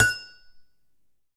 pipePlaced.ogg